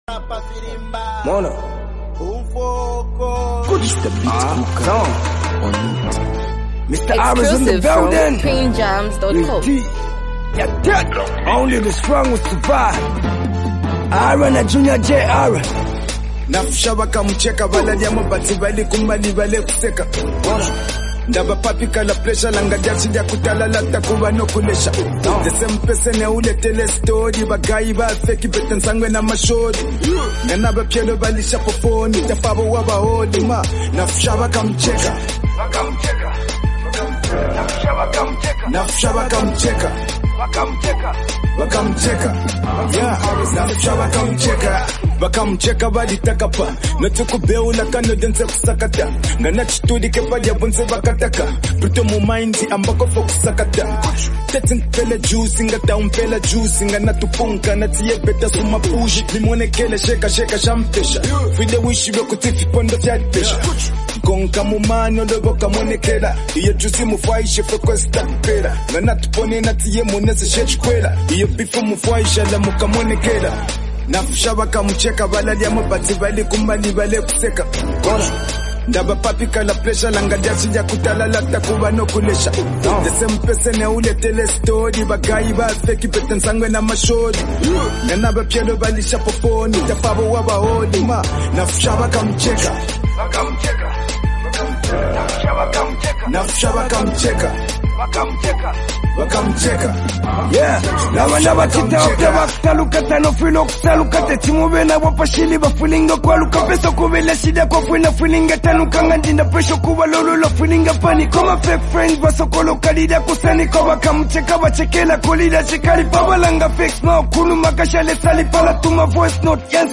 uplifting and motivational song